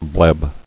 [bleb]